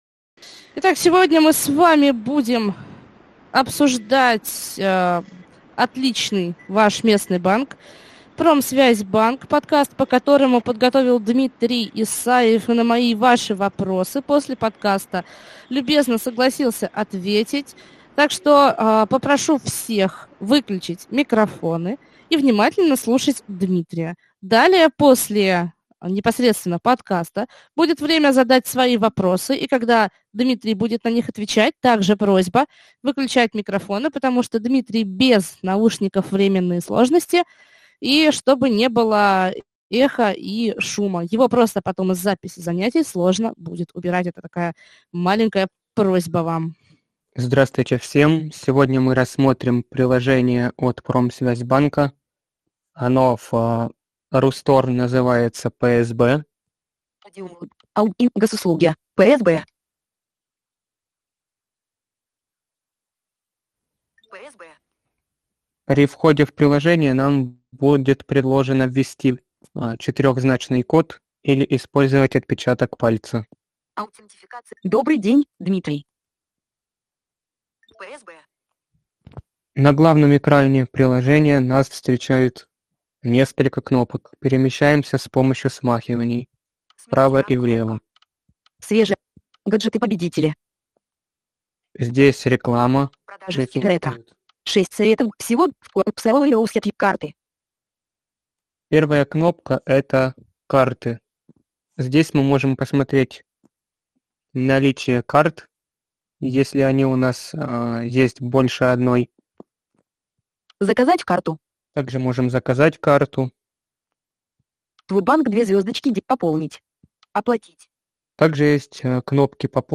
Онлайн занятия о приложении Промсвязьбанка - Центр "Камерата"
Запись занятия